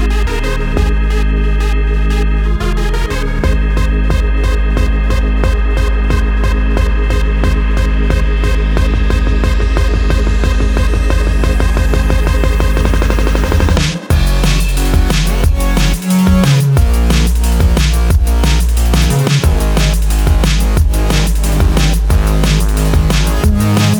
no Backing Vocals Dance 4:28 Buy £1.50